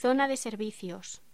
Locución: Zonas de servicios
voz